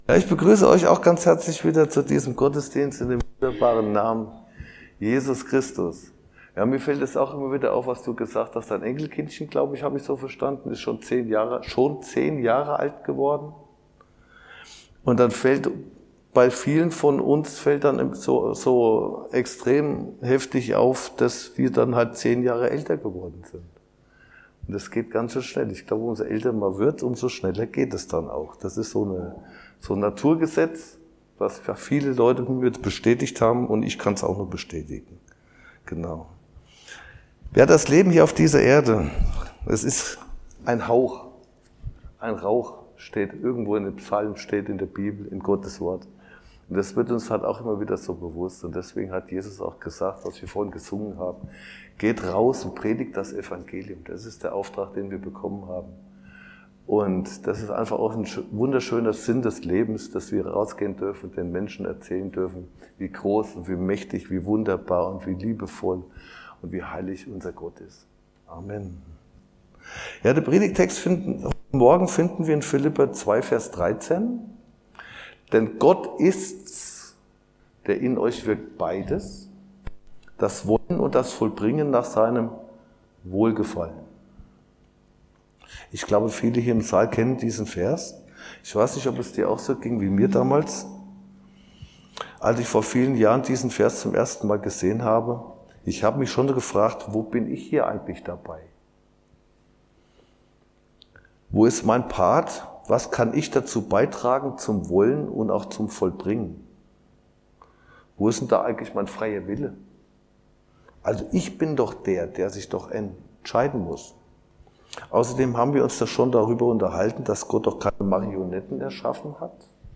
Philipper 2:13 Dienstart: Predigt Gott gibt das wollen und er gibt das vollbringen